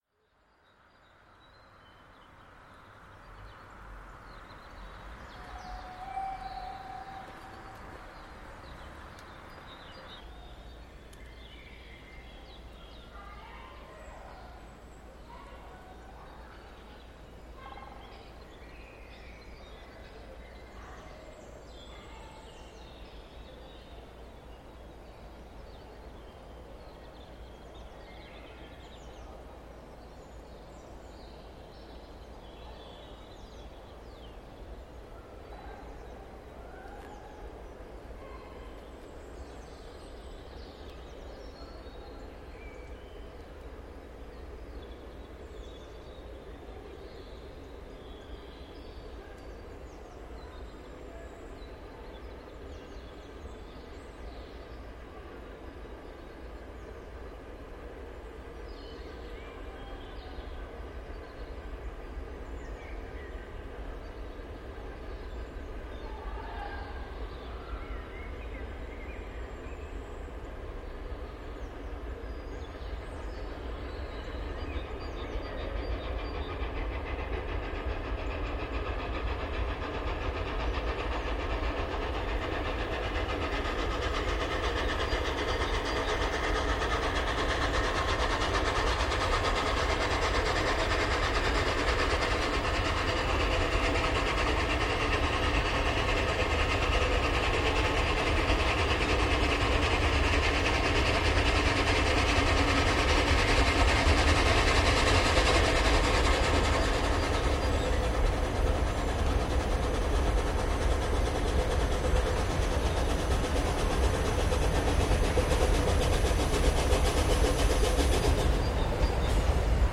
99 3315  mit Zug Bad Muskau→Weißwasser hinter Bad Muskau in der Steigung im Waldrand etwas weiter oben, Spezialhalt und sehr schön brillante Wiederanfahrt wegen Zustieg von zwei Fotografen (= WEM-Mitglieder), um 18:16h am 20.04.2025.   Hier anhören: